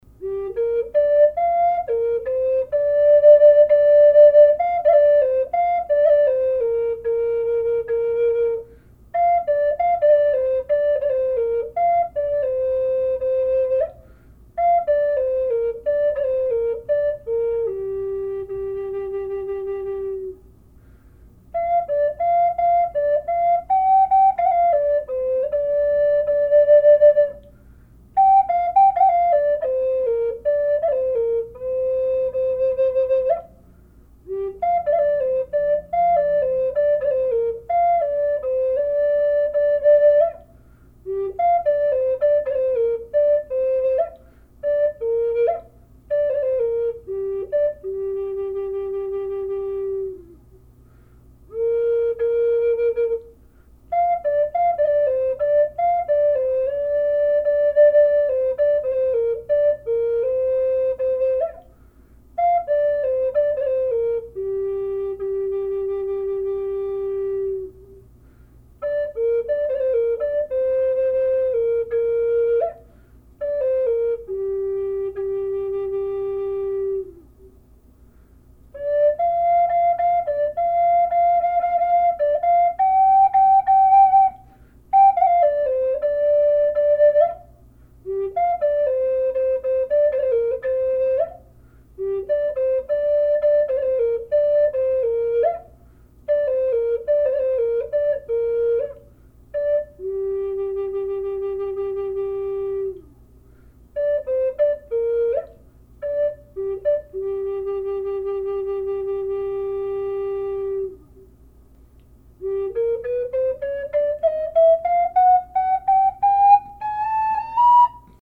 Beautiful Oregon Walnut Burl and Turquoise inlay Spring Flute in mid Gm.
Gorgeous voice & tone.
g-walnut-burl-spring-flute-1.mp3